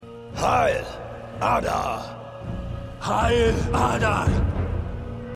HdR_RdM_2x01_Orc1_2.mp3